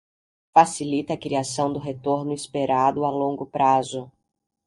Pronounced as (IPA)
/is.peˈɾa.du/